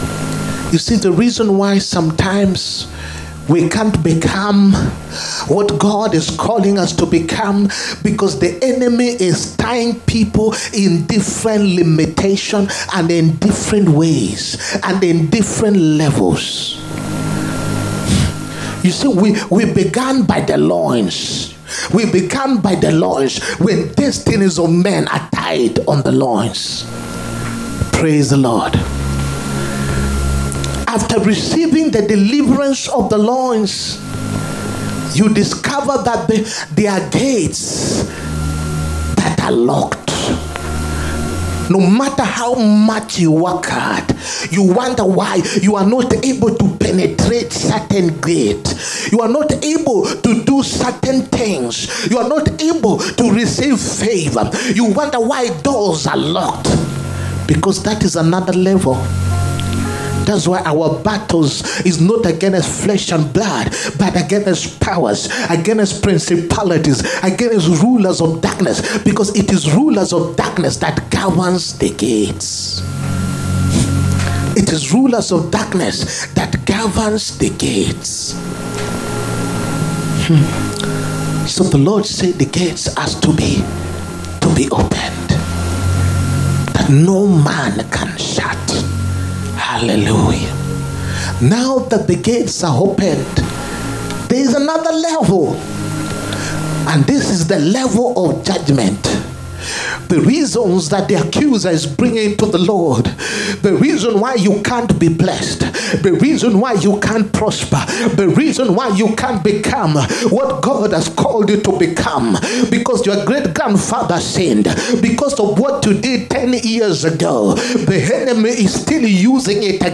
HEALING, PROPHETIC AND DELIVERANCE SERVICE. 22ND FEBRUARY 2025.